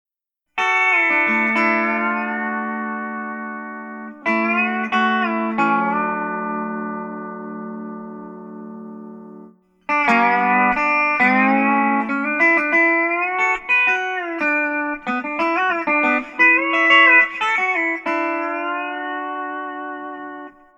It allows pedal steel players to emulate the sound of a Dobro or PedaBro resonator-style guitar.
dobro-pedabro-tonex-audio-sample.mp3